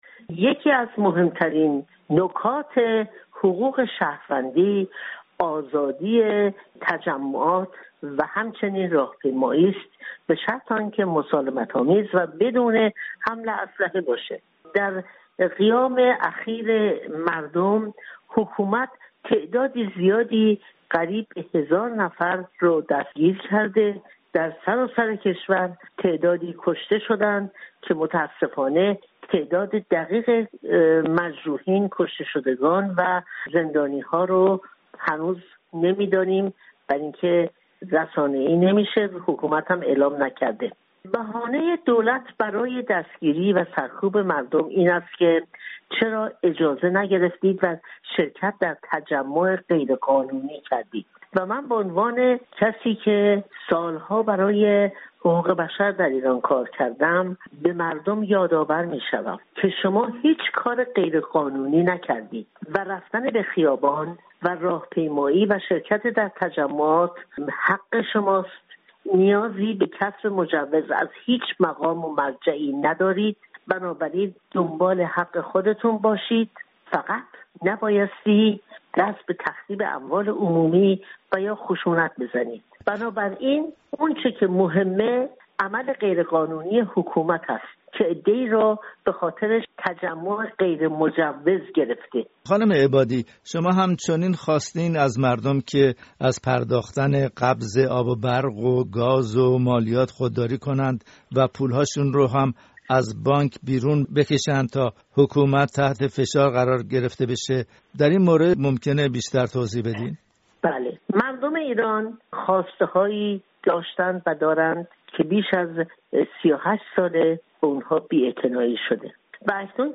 گفت‌وگو با شیرین عبادی، برنده نوبل صلح، درباره اعتراضات اخیر ایران